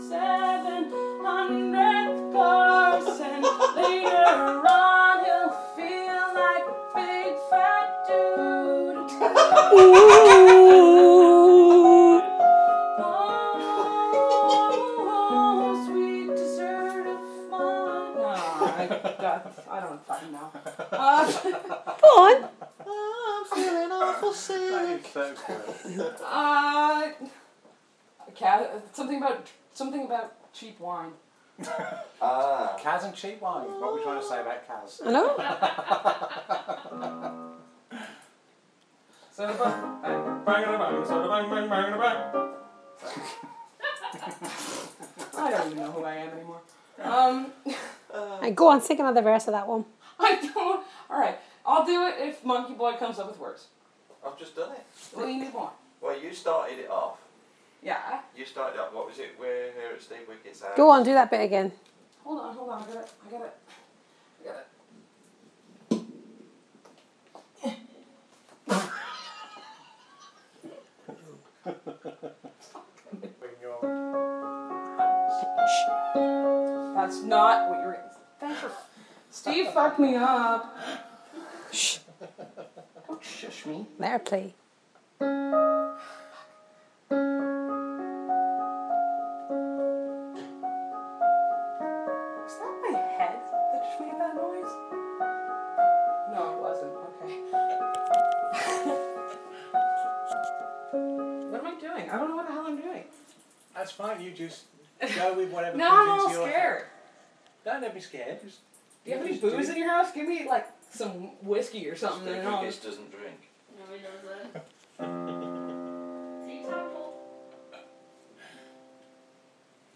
Singing and piano playing barb style